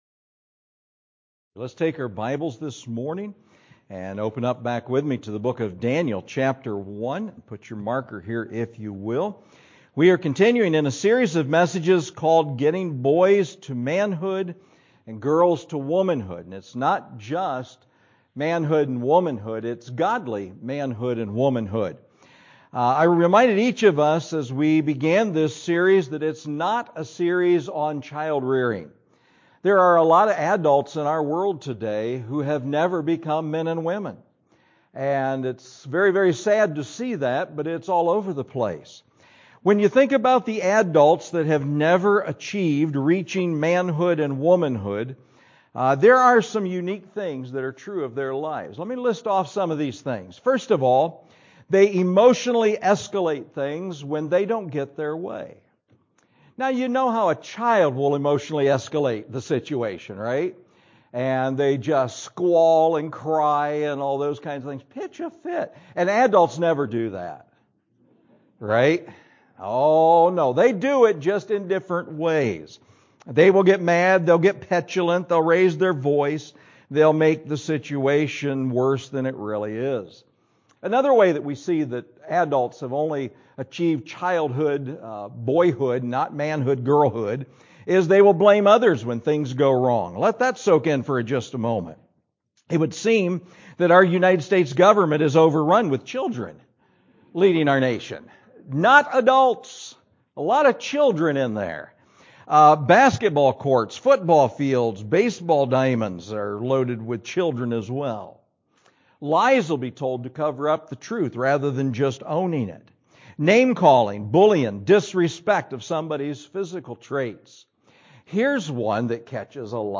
Getting Boys To Godly Manhood, Girls To Godly Womanhood – III – AM – 10/1/23 – First Baptist Church Bryan